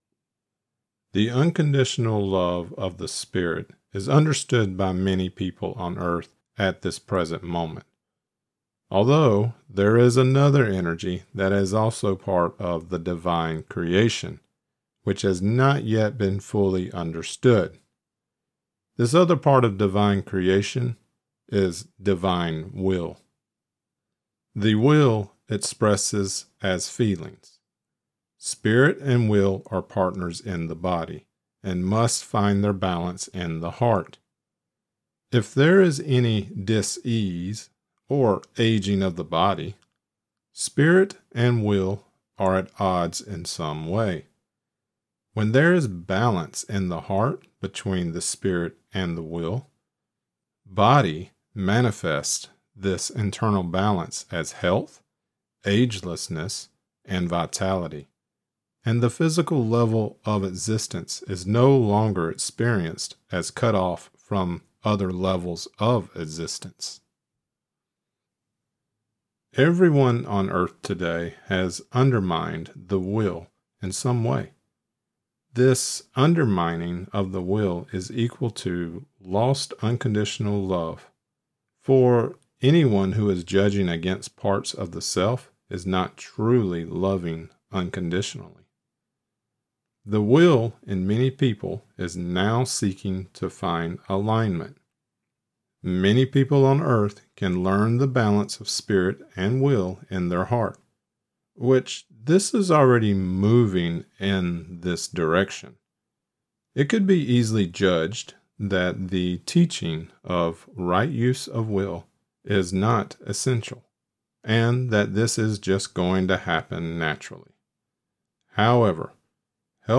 Lecture Created Transcript Blockchain Right Use of Will 08/22/2025 Right Use of Will (audio only) 08/22/2025 Watch lecture: View 2025 Lectures View All Lectures